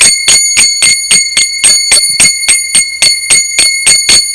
Ritmo muy sencillo en el que se alternan continuamente las manos.
TAkataka takataka // TAkataka takataka